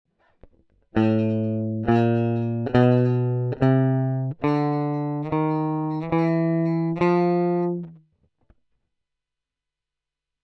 Einfach gehalten, Clean und kein starker Anschlag bei 2mm Saitenhöhe.